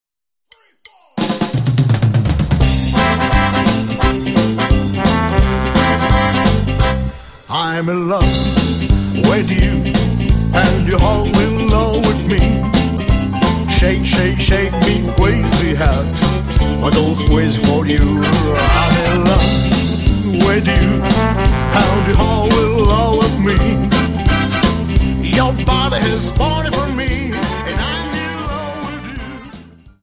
Dance: Jive